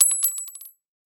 bullet-metal-casing-drop-4.mp3